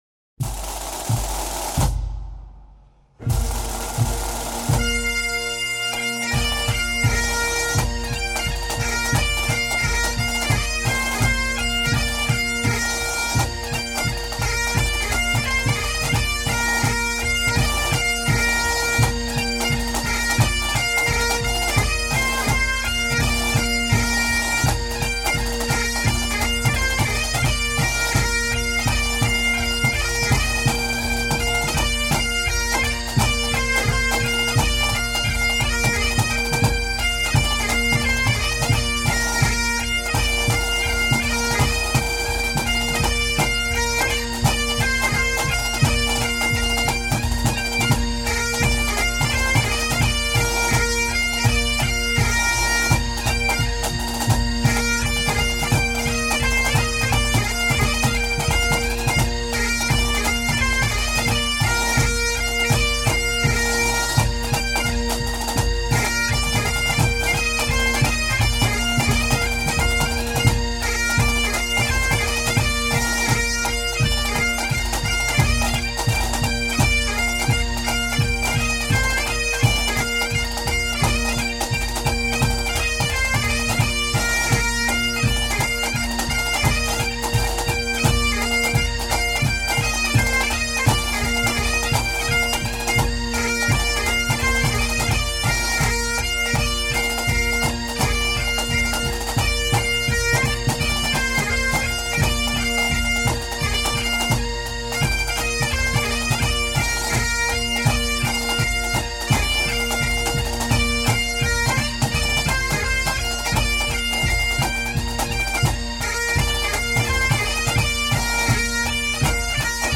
其中有几段小军鼓与风笛演奏的曲子很正点，这是首传统的曲子